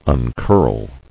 [un·curl]